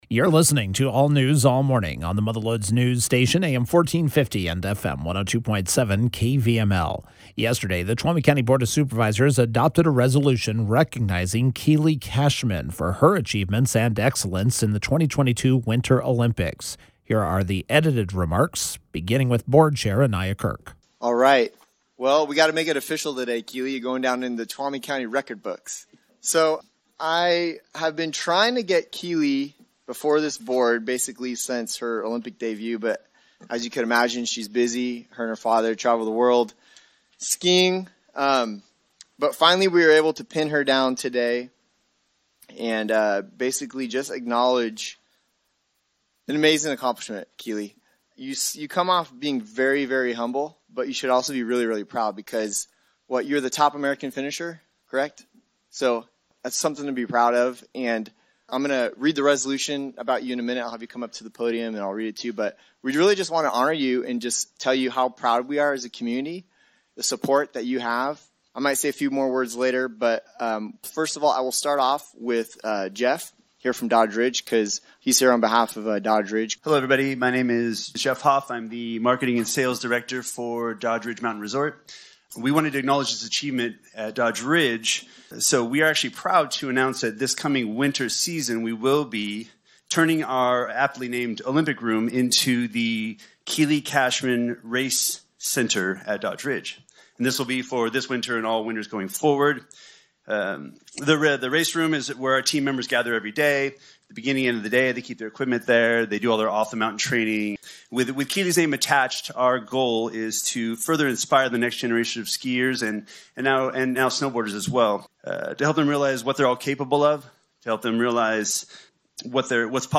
Olympic downhill skier Keely Cashman was on hand at Tuesday’s Tuolumne County Board of Supervisors meeting to receive a special resolution highlighting her accomplishments.